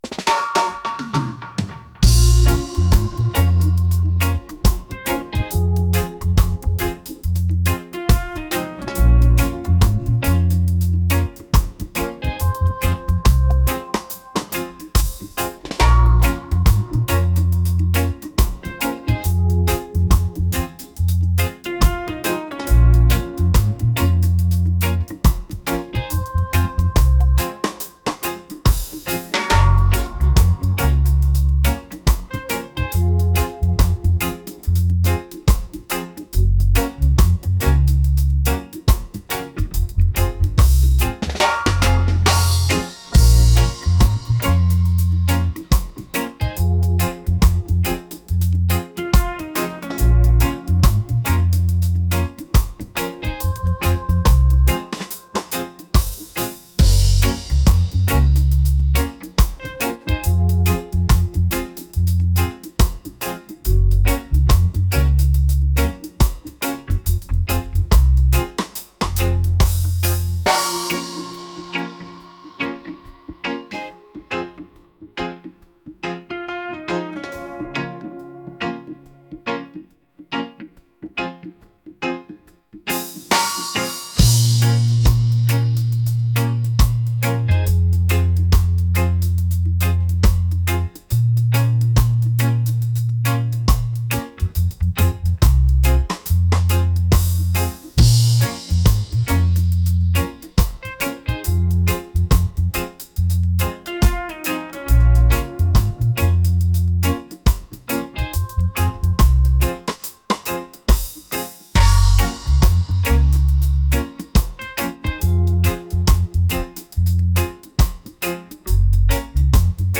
reggae | romantic